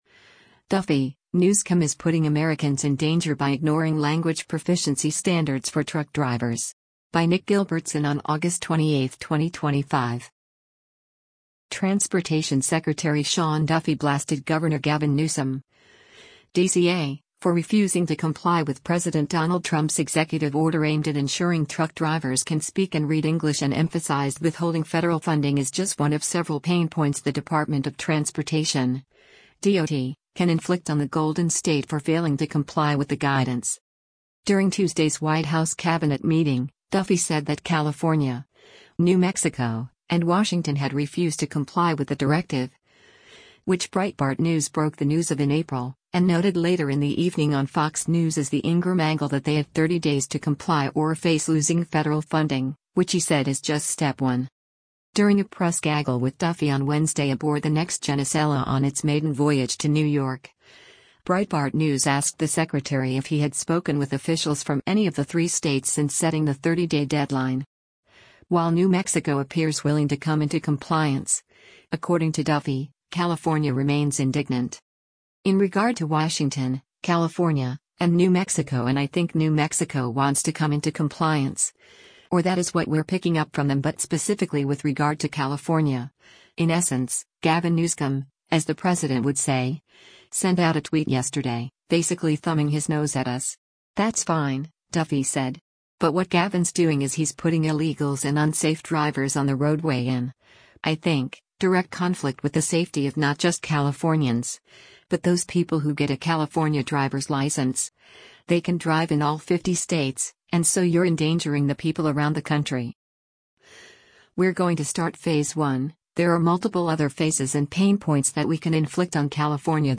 During a press gaggle with Duffy on Wednesday aboard the next-gen Acela on its maiden voyage to New York, Breitbart News asked the secretary if he had spoken with officials from any of the three states since setting the 30-day deadline.